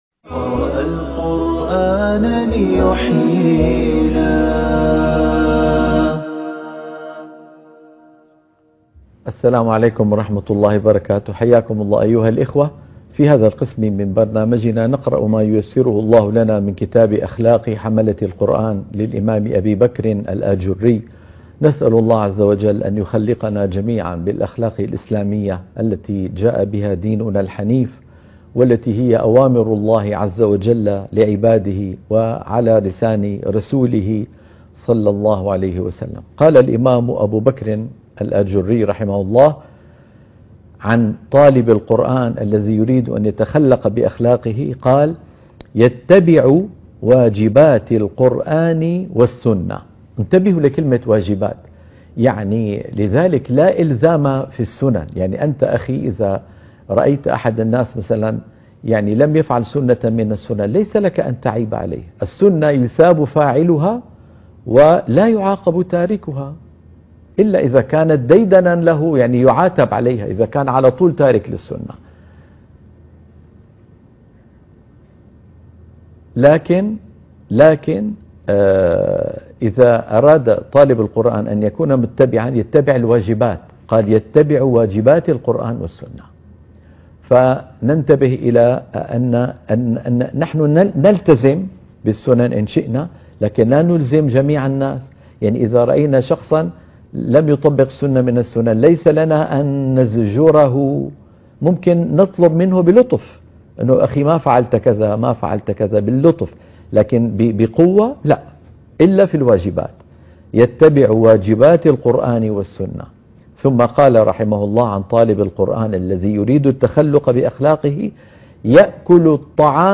قراءة كتاب أخلاق حملة القرآن - الحلقة 15